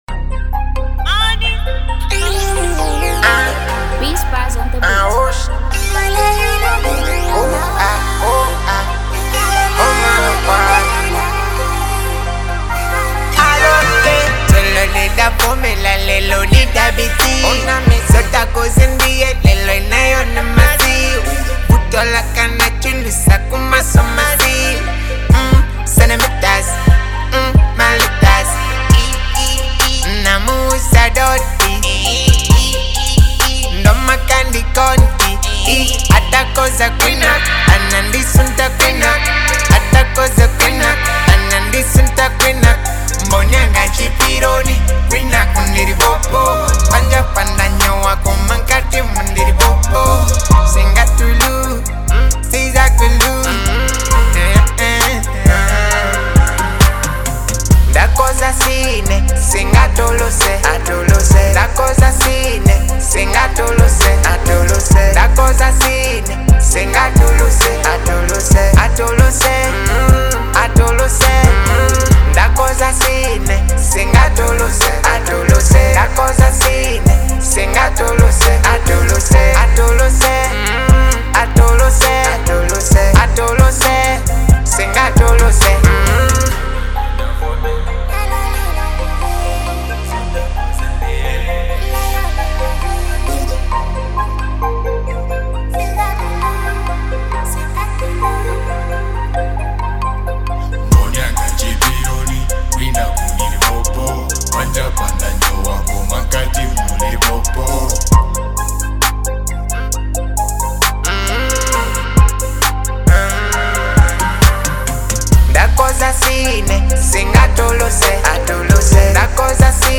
Trap Music